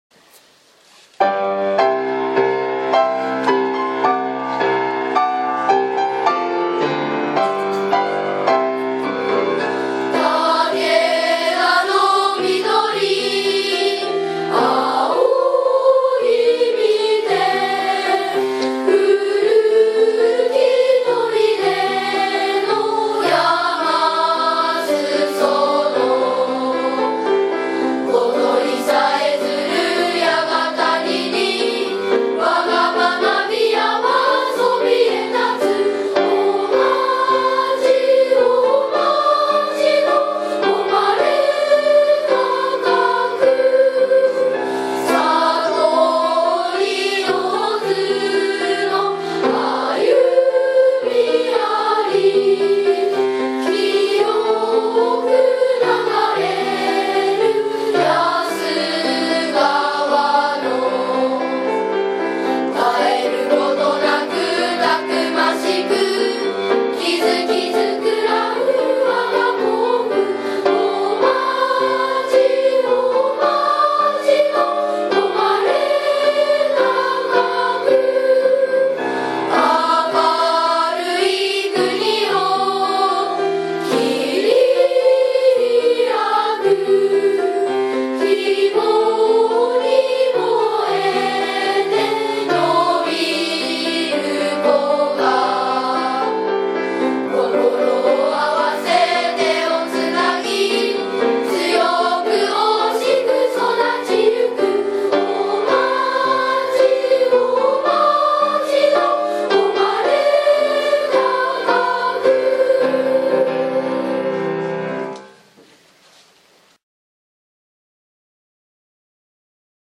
大町小学校　校歌